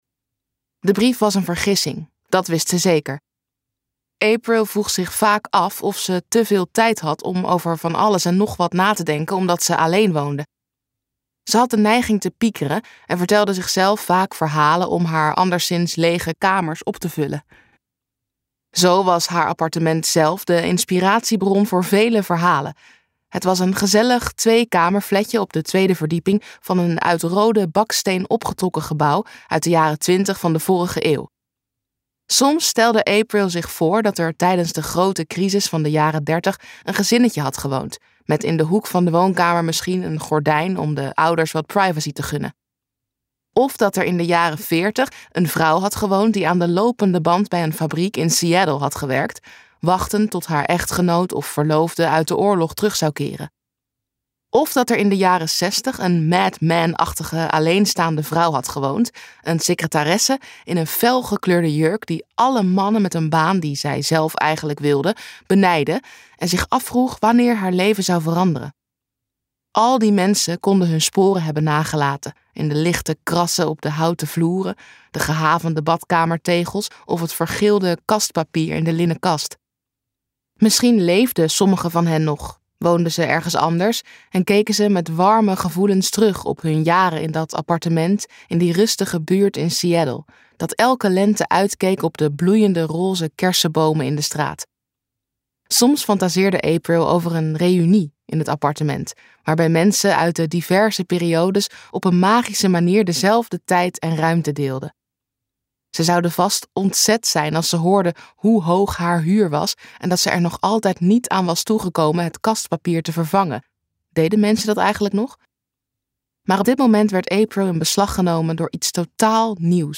Ambo|Anthos uitgevers - Storybook ending luisterboek